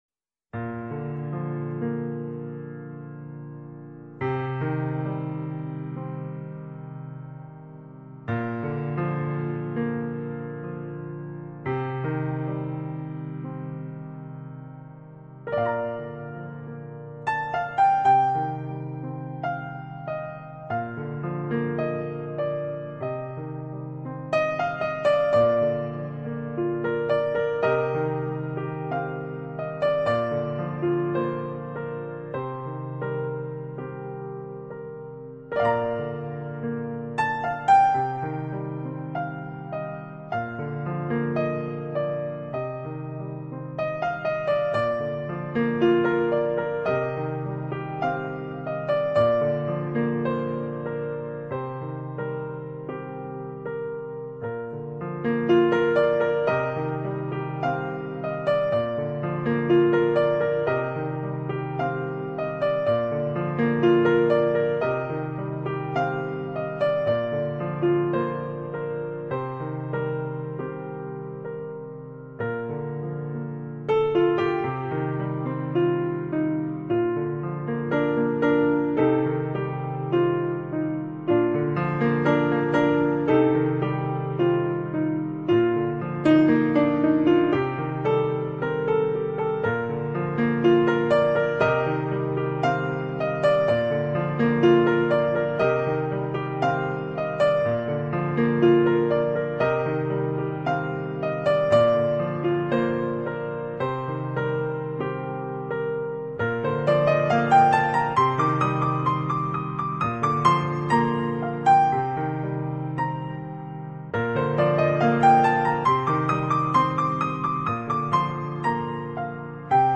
【柔美钢琴】